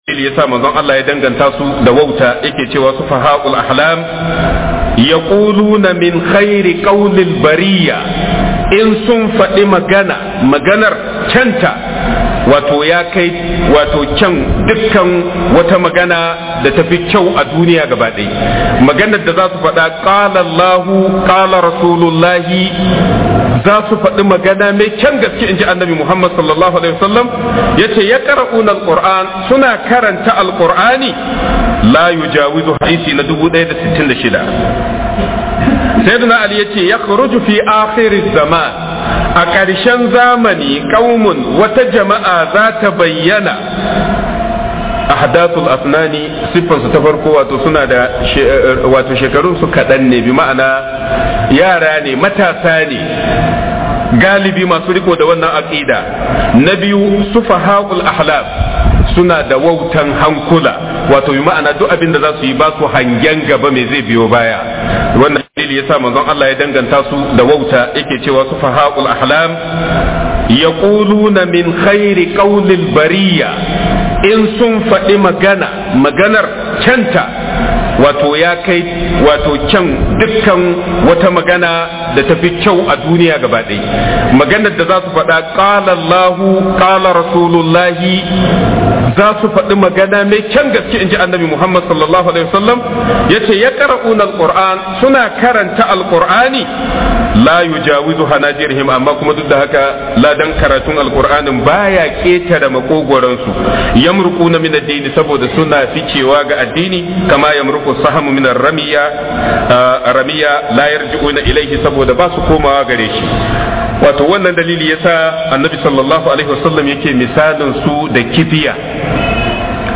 Book MUHADARA